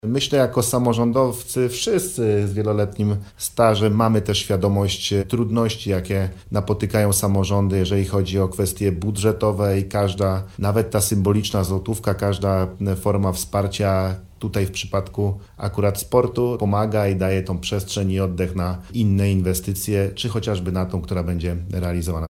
Miejmy nadzieję, że na tym obiekcie będą trenować przyszli olimpijczycy, mówi Paweł Gancarz – Marszałek Województwa Dolnośląskiego.